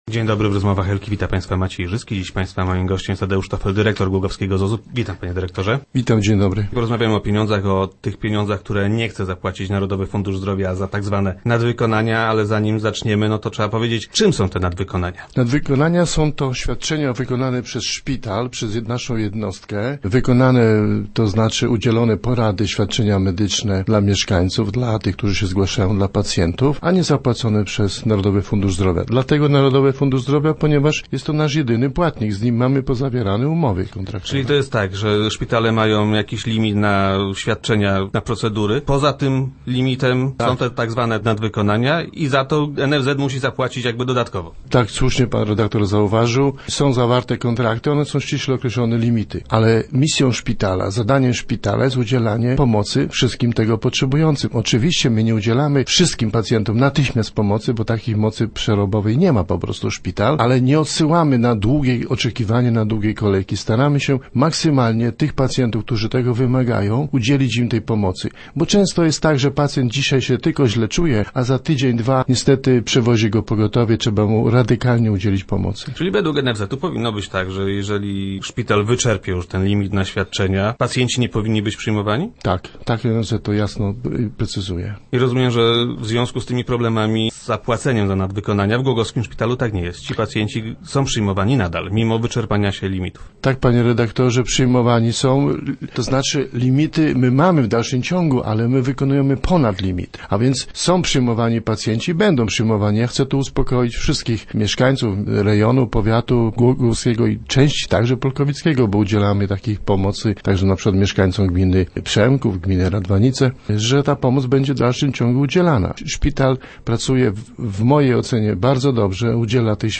16.09.2011. Radio Elka